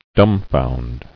[dum·found]